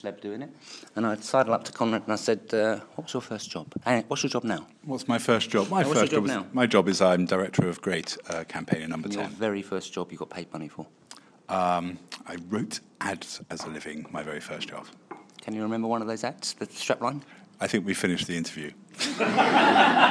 Just a demo of Audioboo at Downing Street.